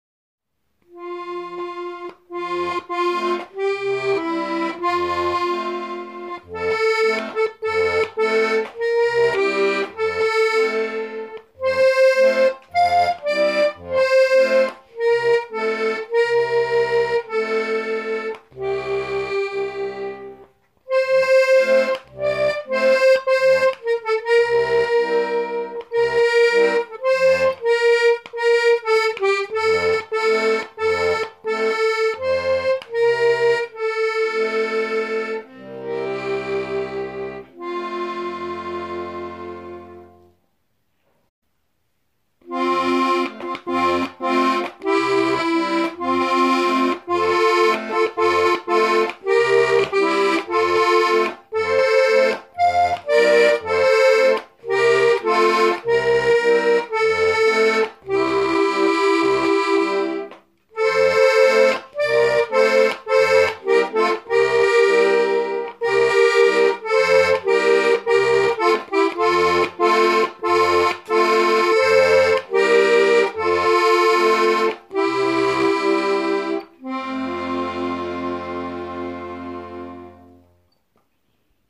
Dürfte aus Frankreich kommen (Musette)?